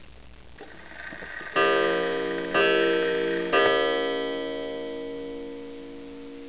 Waterbury Eight Day Steeple Clock
The clock features it's original dial, with club hands (my favoutite type), original pendulum, gong, and pendulum holder (piece of wire inside the case).
Wat_8_Day_Steeple.wav